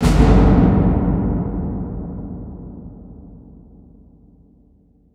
VEC3 FX Reverbkicks 01.wav